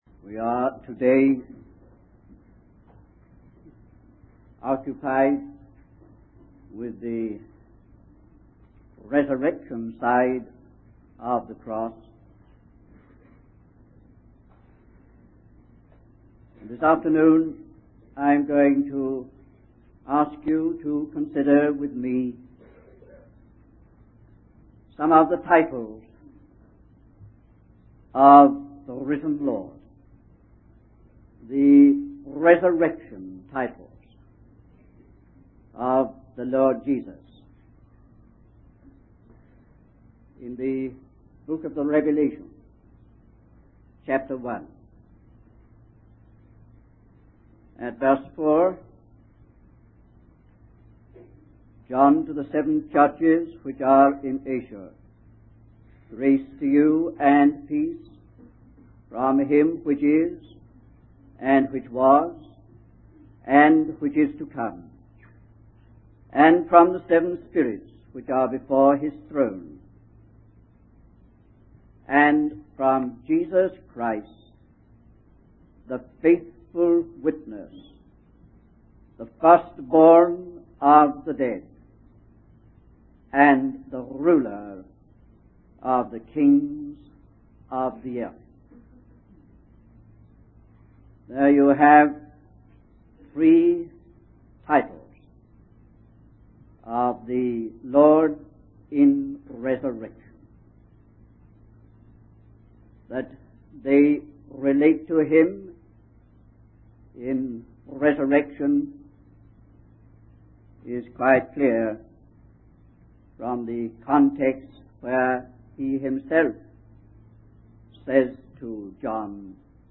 In this sermon, the speaker focuses on the three titles of Jesus Christ mentioned in the book of Revelation: the faithful witness, the firstborn of the dead, and the ruler of the kings of the earth. These titles represent what Jesus has proven himself to be in his incarnation, triumph over death, and exaltation by divine vindication. The speaker emphasizes the importance of understanding Jesus not just through his teachings and actions, but by delving deeper into his heart, mind, and spirit.